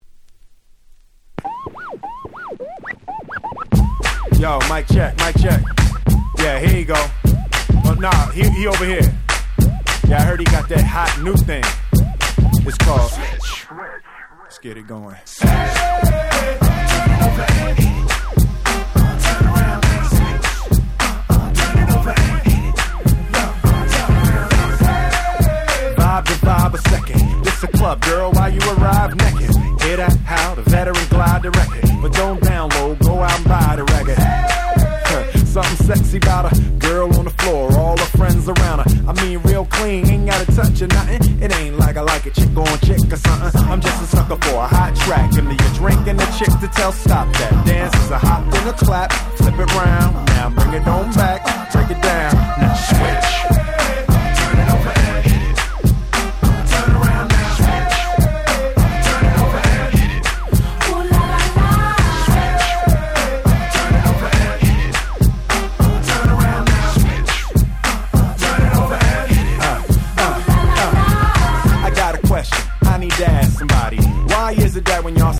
05' Super Hit Hip Hop !!
アッパーなクラップBeatにキャッチーなサビが印象的なスーパーヒット！！